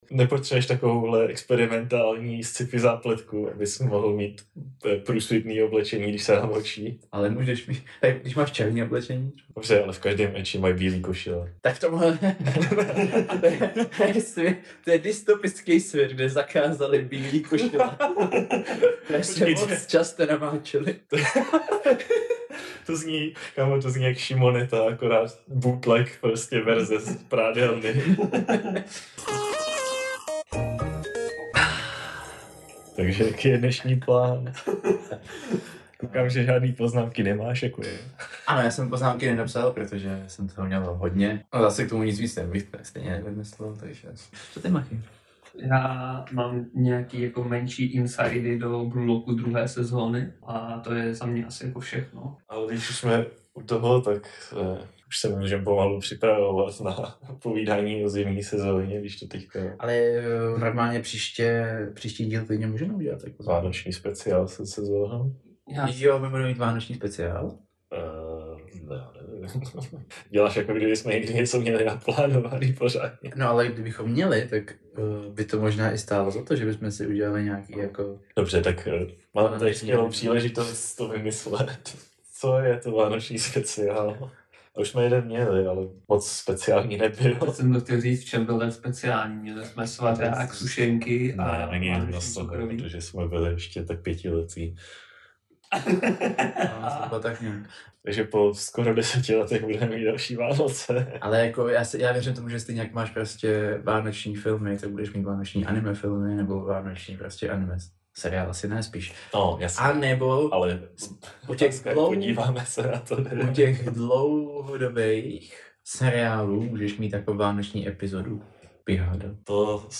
Smějeme se každé blbosti a navzájem po sobě házíme plyšáky.